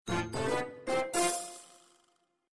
level_win.mp3